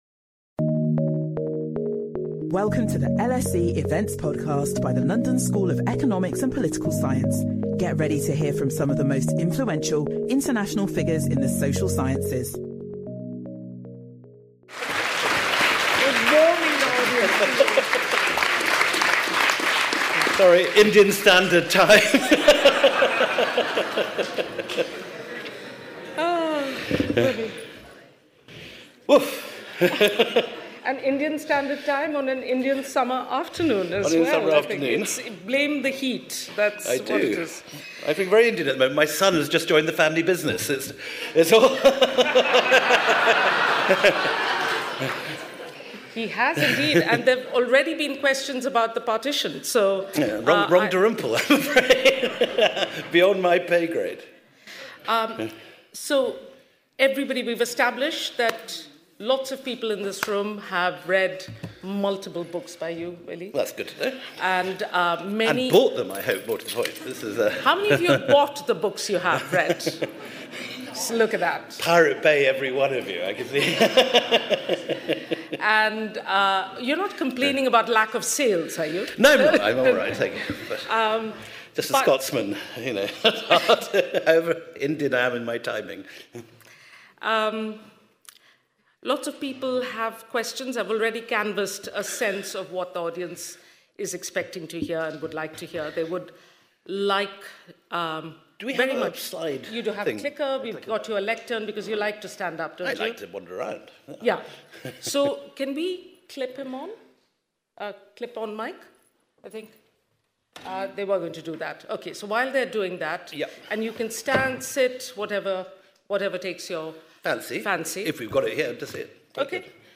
This recording contains strong language.